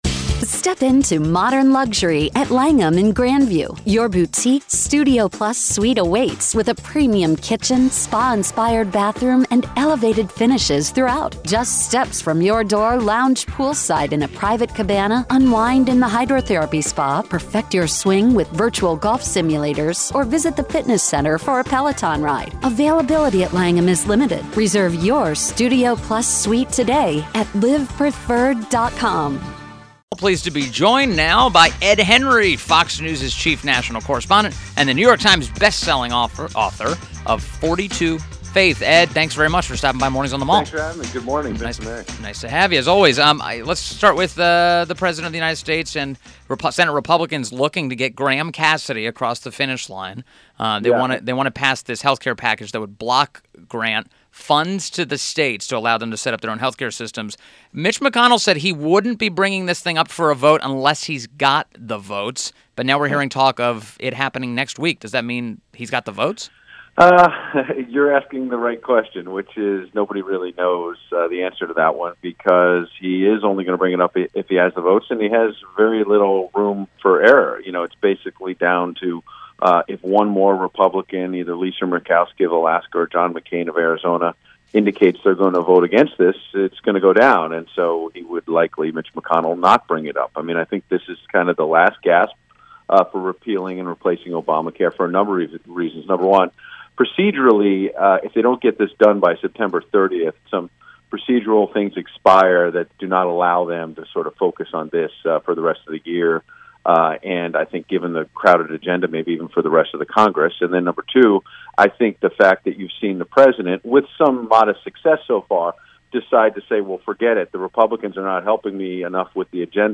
WMAL Interview - ED HENRY - 09.22.17